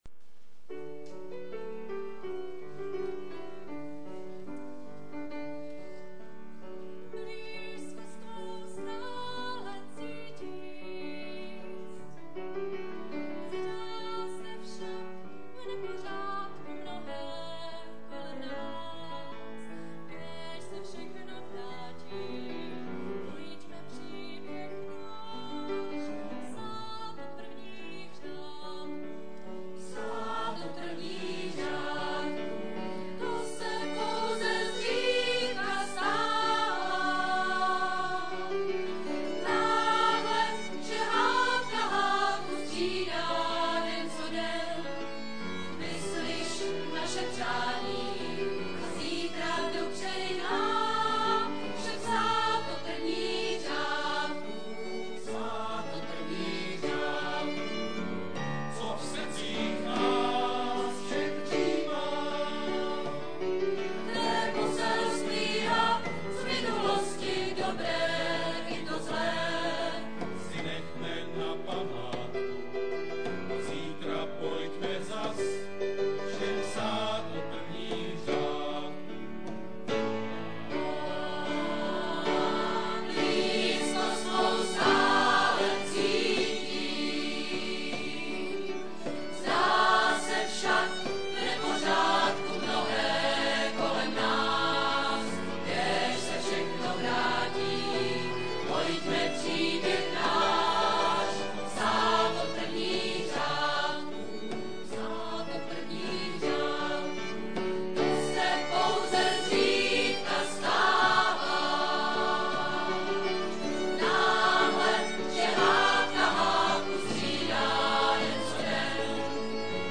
Muzikálové melodie